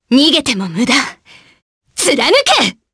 Selene-Vox_Skill8_jp_b.wav